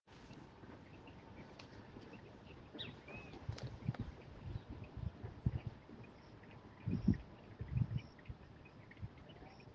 Ranita del Zarzal (Boana pulchella)
Nombre en inglés: White-banded Tree-frog
Localidad o área protegida: Turdera
Condición: Silvestre
Certeza: Vocalización Grabada